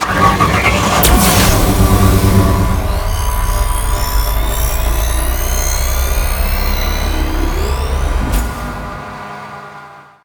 CosmicRageSounds / ogg / vehicle / start.ogg